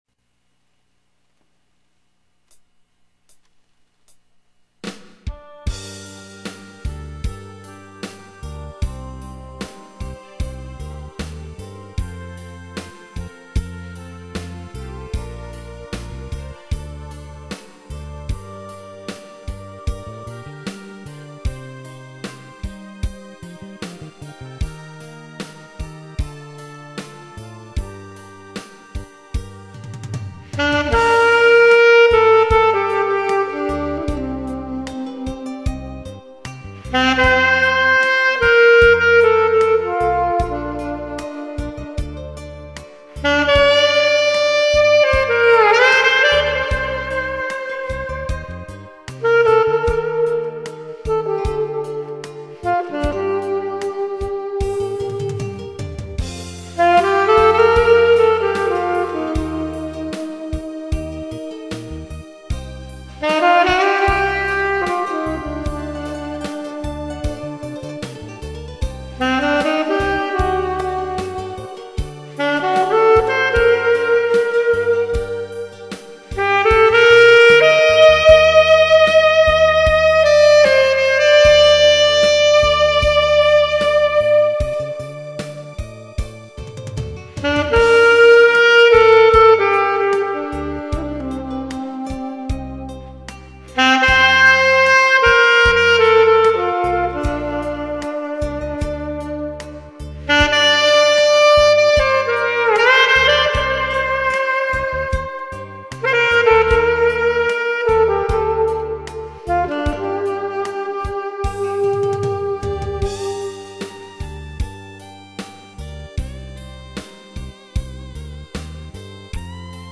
아마추어의 색소폰 연주
오후 2시부터 밤9시까지 녹음에 매달렸습니다.
앨토색소폰연주로
테너는 푸근한 맛이 있지만 앨토는 좀 카랑카랑한 맛이 있지요
부족한 초보연주지만 애교로 예쁘게 들어주시길......